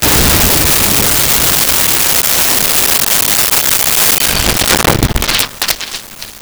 Explosion Large 4
Explosion Large_4.wav